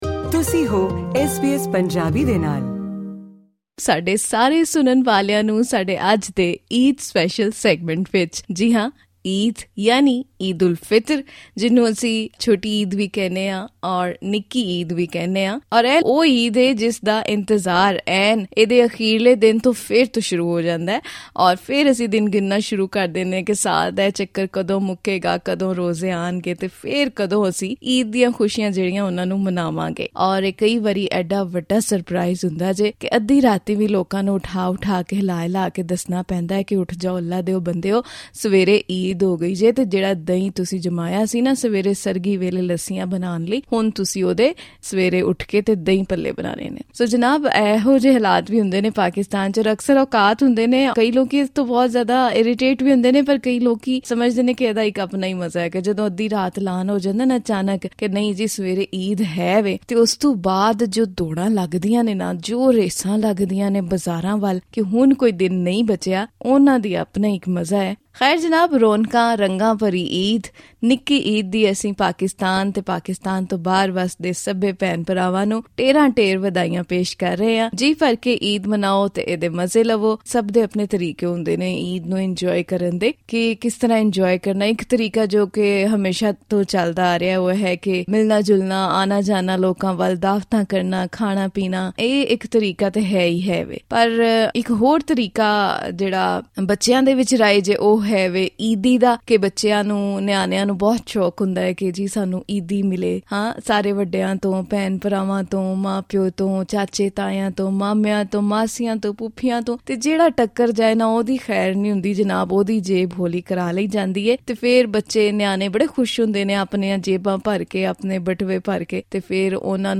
How is it being celebrated across Pakistan? Listen to special report on this